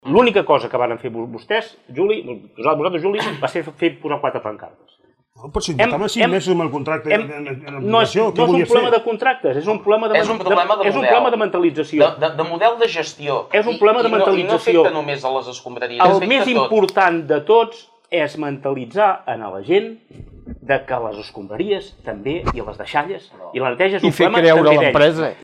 Debat Electoral Palafrugell 2019